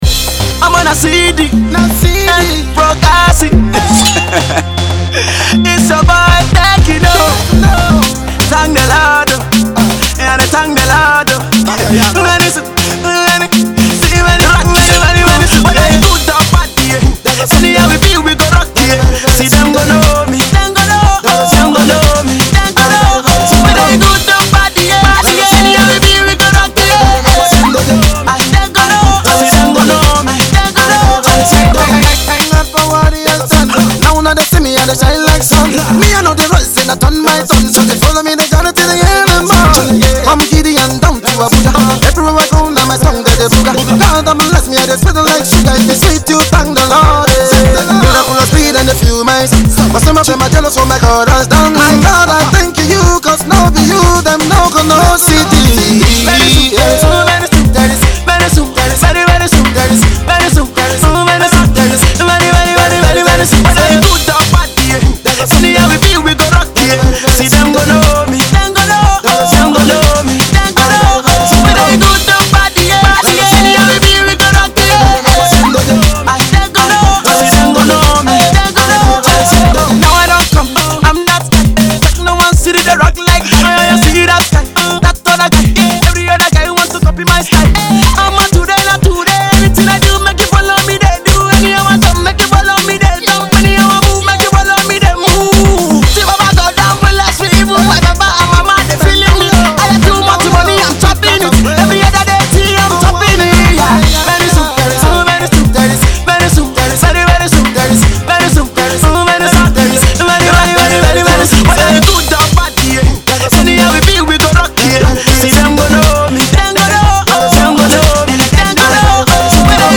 Street-Hop infused singles